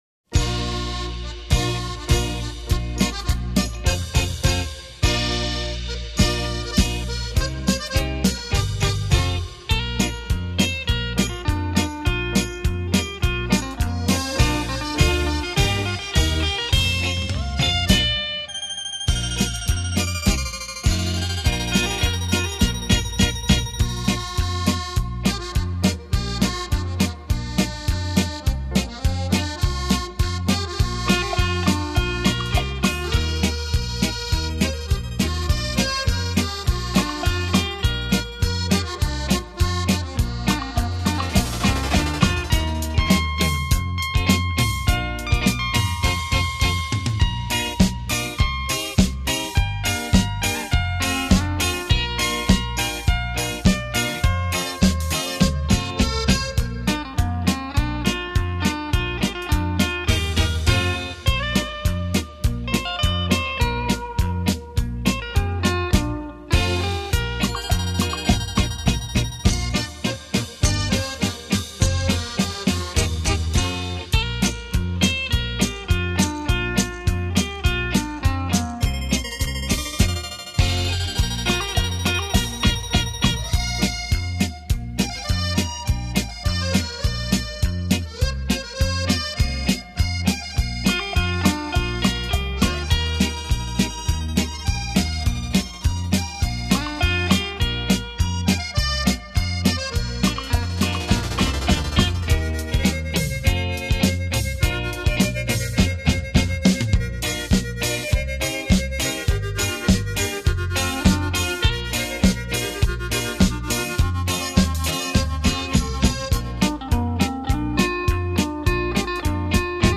火箭筒超低音X-BASS數位環繞音效
大樂團與效果音組合超時空演繹 百萬名琴魅力大出擊
（手風琴魅力演奏）